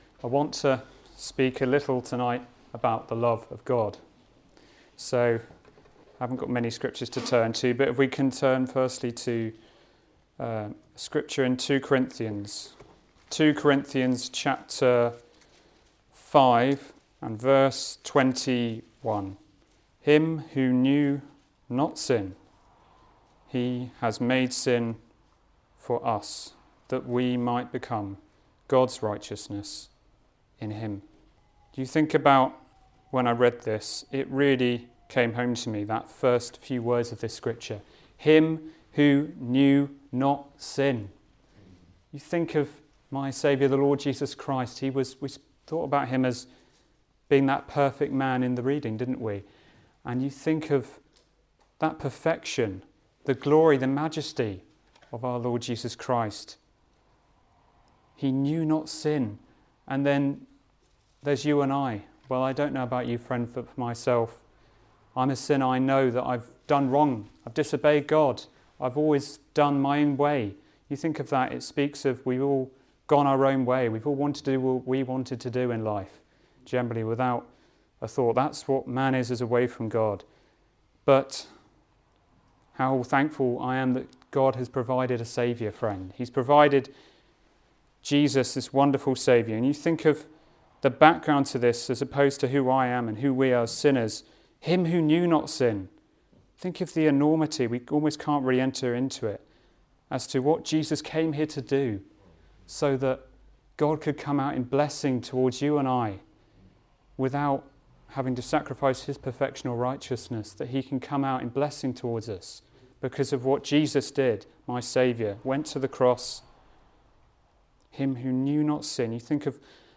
Gospel Preachings